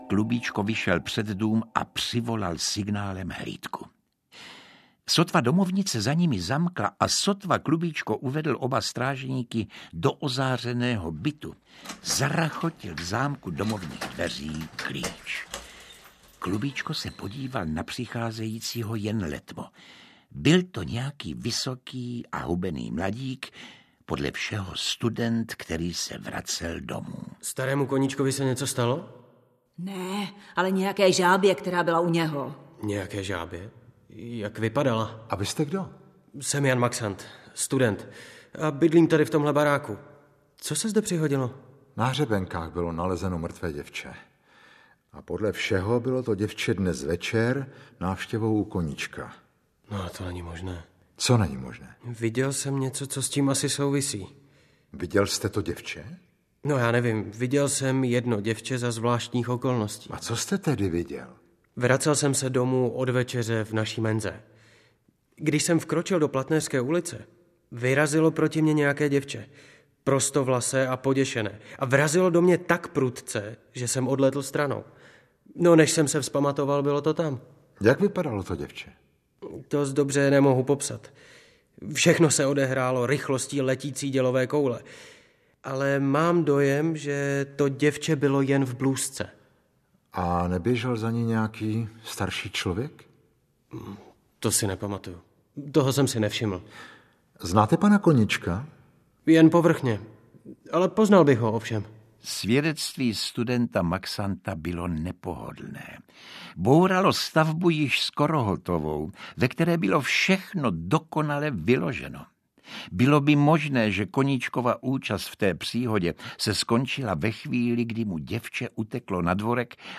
Audiokniha Zlá minuta, kterou napsal Emil Vachek - obsahuje detektivní příběh, v němž exceluje v roli detektiva Klubíčka Marek Eben.
Ukázka z knihy